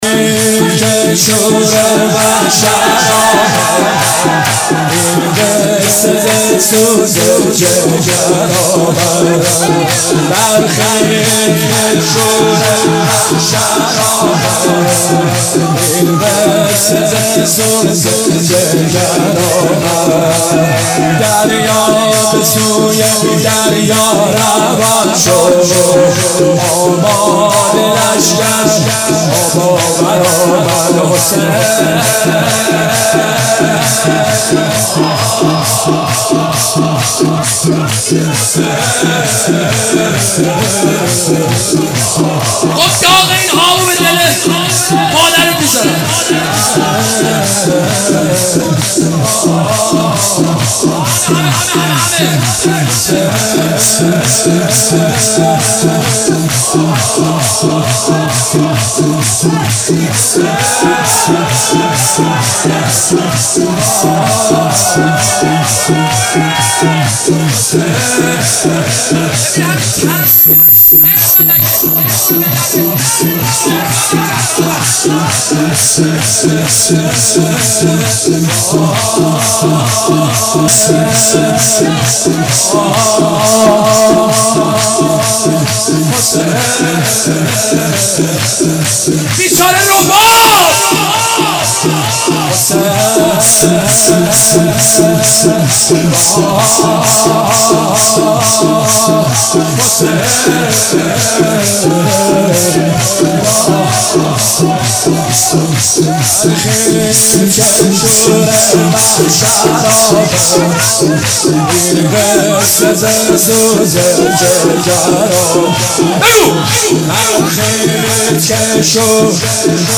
مدح